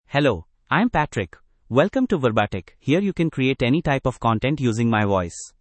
MaleEnglish (India)
Patrick is a male AI voice for English (India).
Voice sample
Listen to Patrick's male English voice.
Male
Patrick delivers clear pronunciation with authentic India English intonation, making your content sound professionally produced.